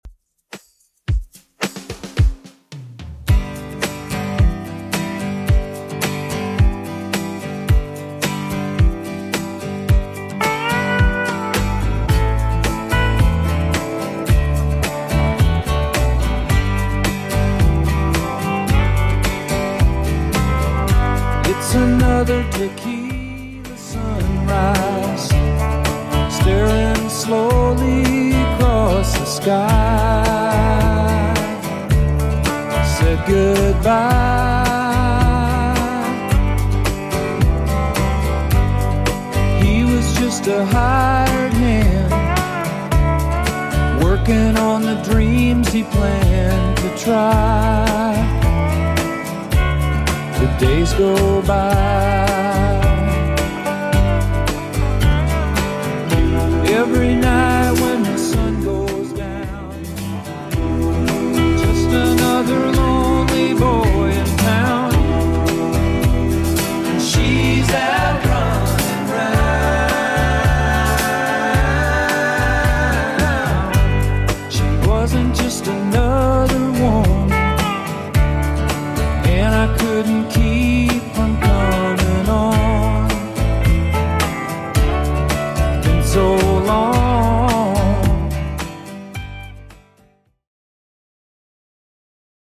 Genre: 80's
BPM: 138